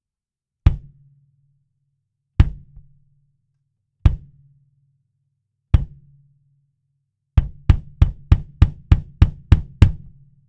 音の心象は、実にタイトでスピード感のある音です！
EQ等は一切していません。
バスドラム　IN
25ドラムキックイン.wav